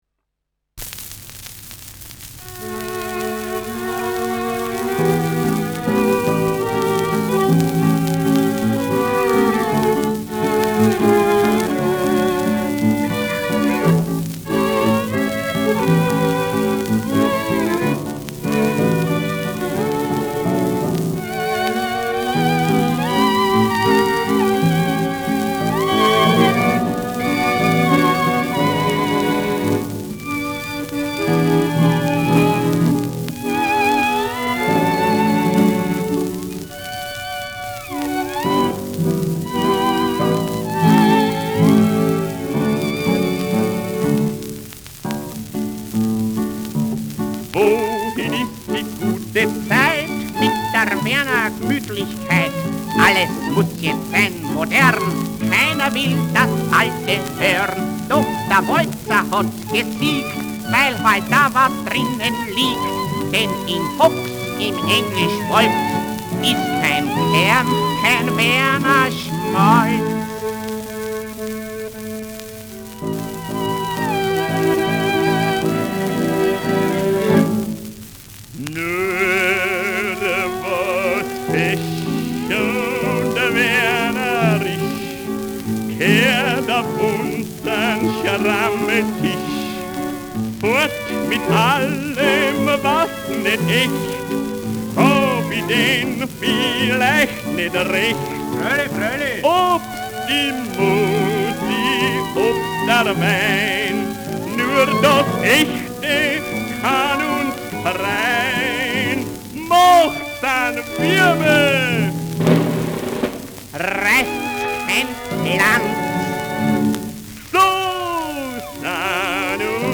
Schellackplatte
präsentes Knistern : leichtes Rauschen